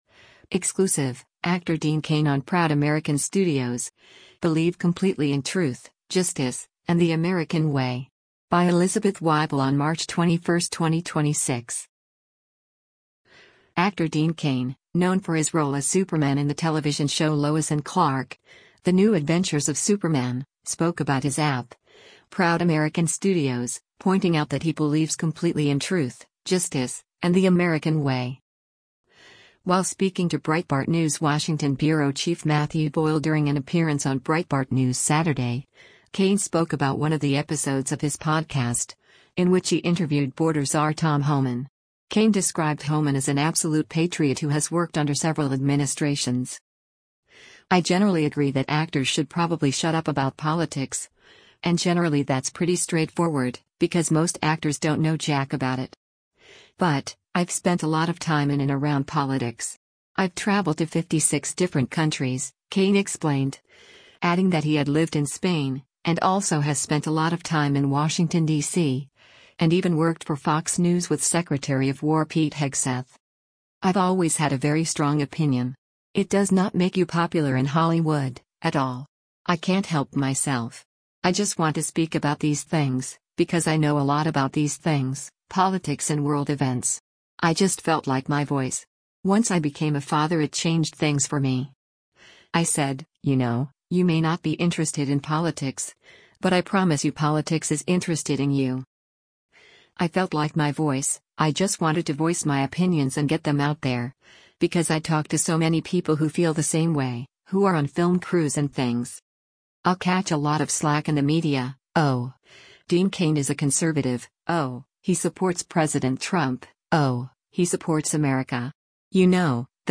Actor Dean Cain, known for his role as Superman in the television show Lois & Clark: The New Adventures of Superman, spoke about his app, Proud American Studios, pointing out that he believes “completely in truth, justice, and the American way.”